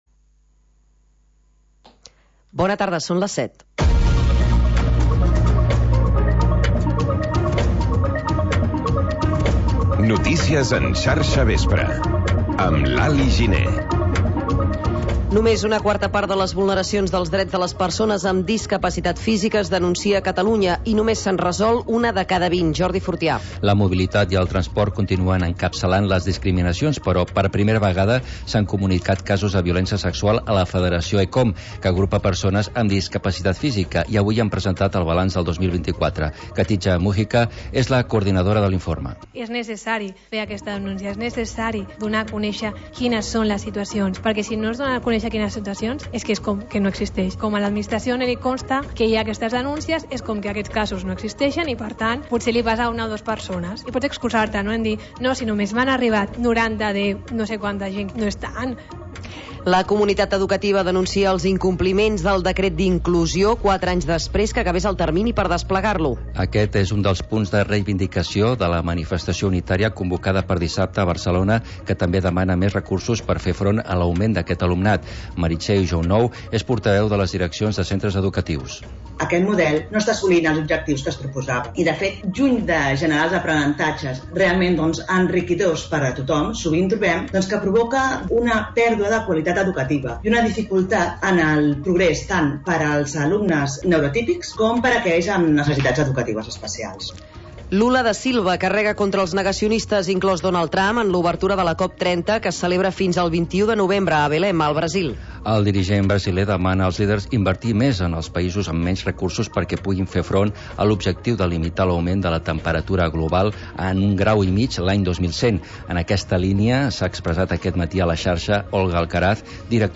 Informatiu que desplega una mirada àmplia sobre el territori, incorporant la informació de proximitat al relat de la jornada.